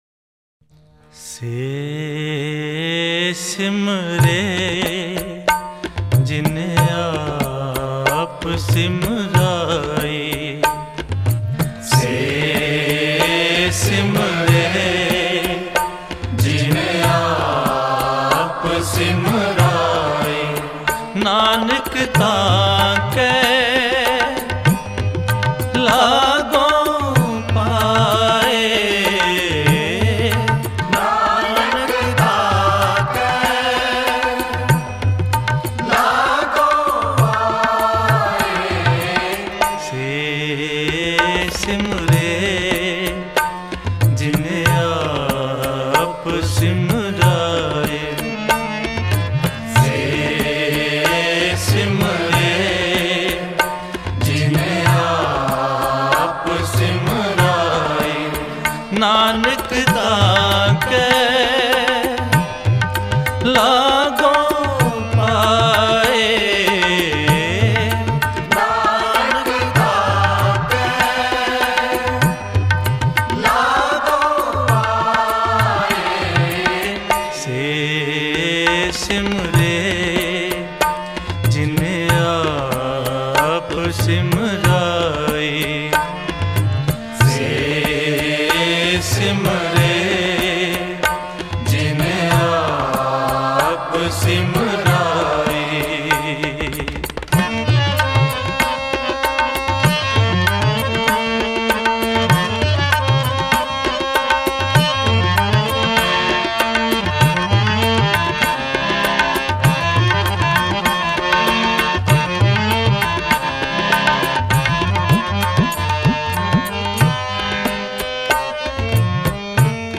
Shabad Gurbani Kirtan Album Info